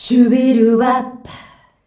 SHUBIWAP C.wav